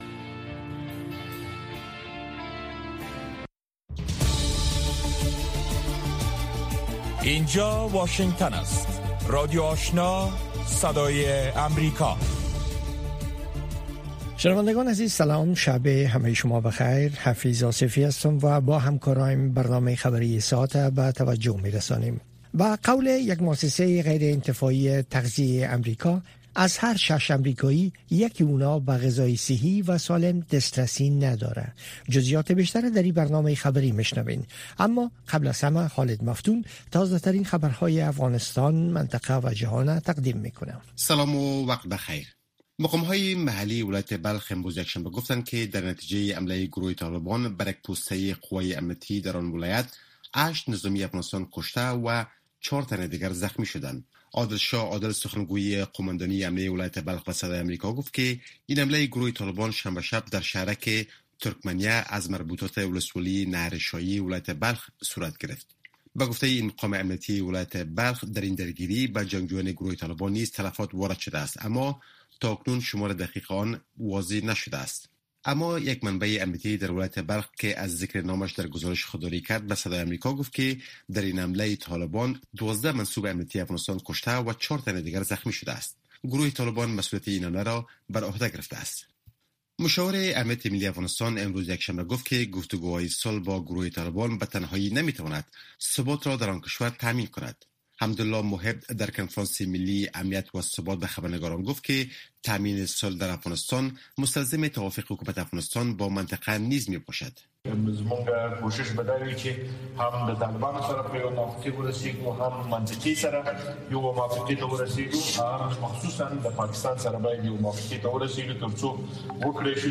در برنامه خبری شامگاهی، خبرهای تازه و گزارش های دقیق از سرتاسر افغانستان، منطقه و جهان فقط در سی دقیقه پیشکش می شود.